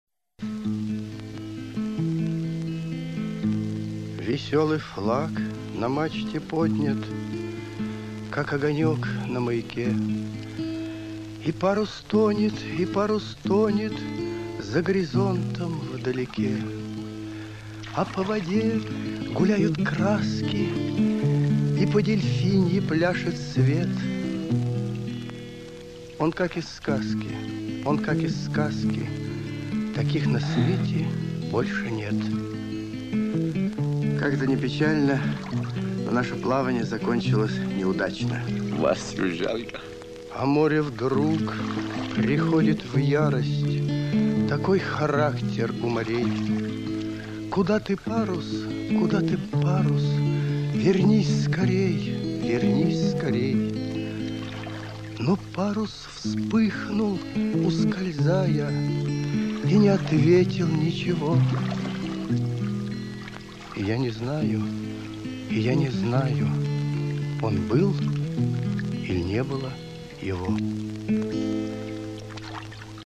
Главная / Computer & mobile / Мелодии / Саундтрек